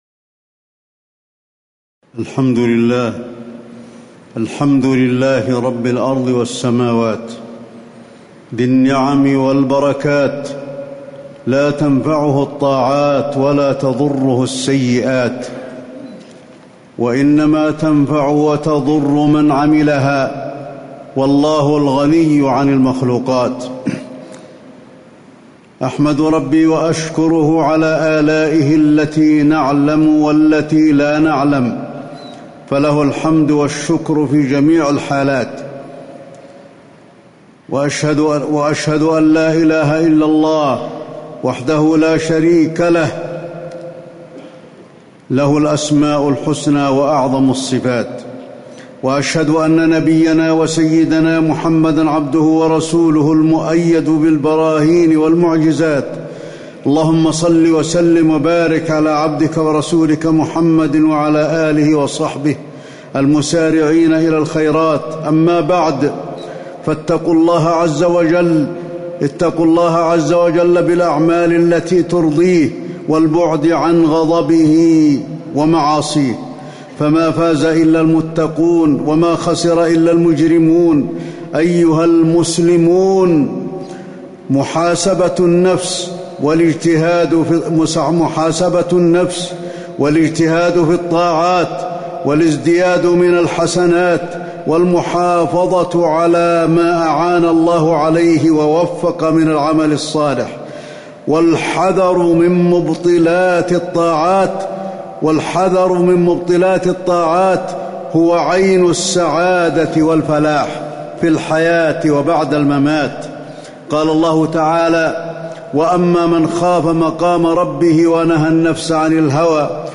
تاريخ النشر ٢٤ رمضان ١٤٣٩ هـ المكان: المسجد النبوي الشيخ: فضيلة الشيخ د. علي بن عبدالرحمن الحذيفي فضيلة الشيخ د. علي بن عبدالرحمن الحذيفي محاسبة النفس في رمضان ونعمة القران